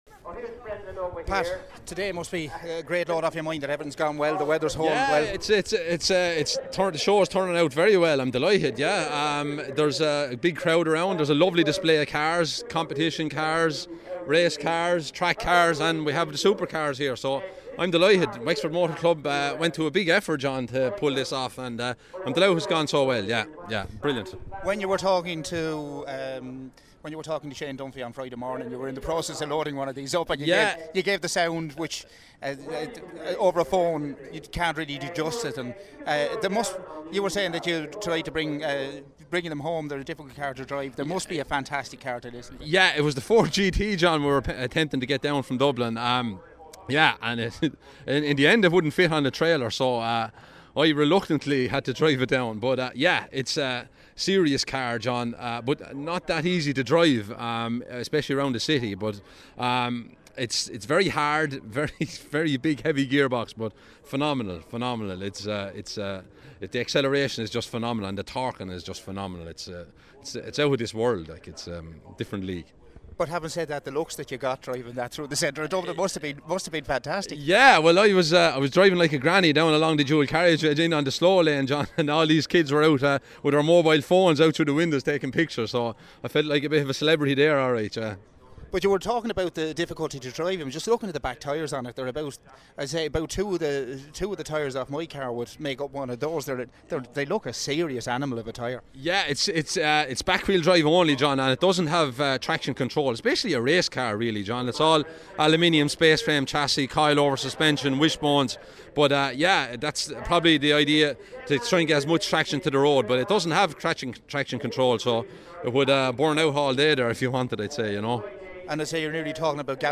Not content with giving you pictures, we also did a few interviews at the launch of the Riverbank House Hotel Dick Bailey Stages Rally.